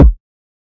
Kick (Champion).wav